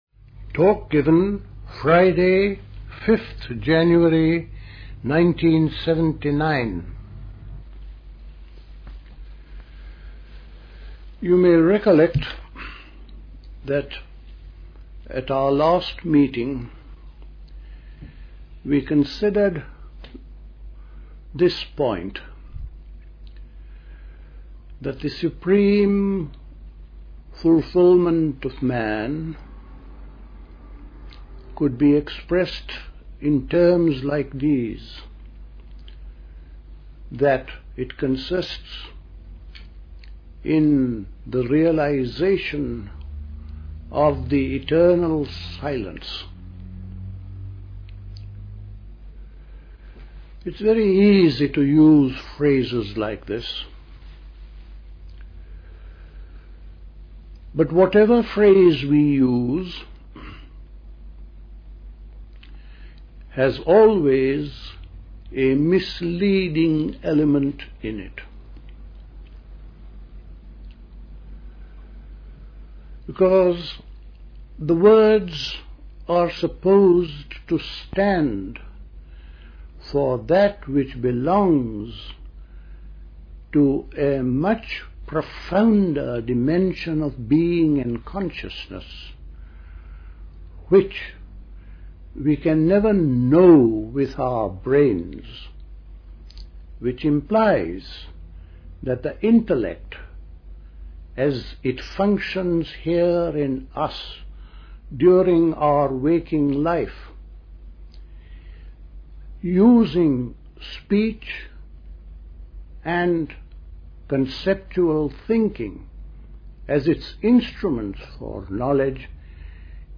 A talk